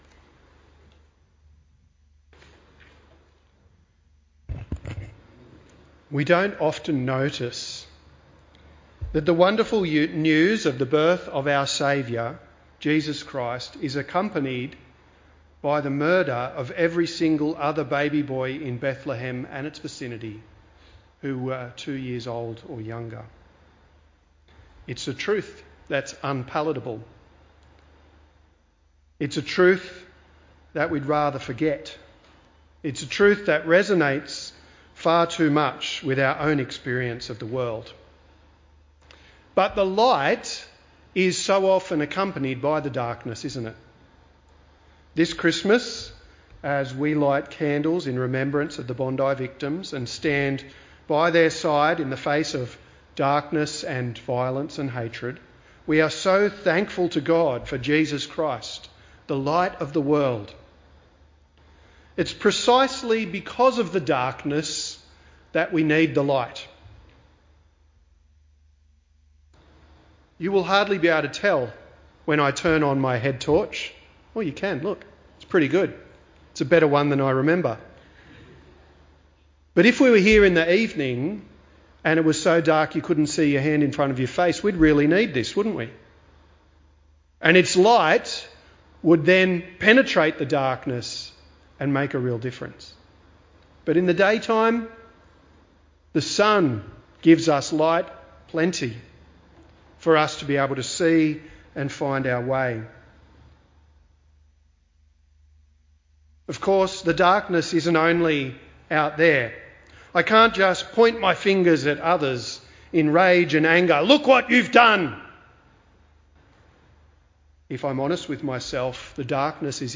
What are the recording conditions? This message was delivered as part of a ‘Lessons and Carols’ service where the readings and carols provide context.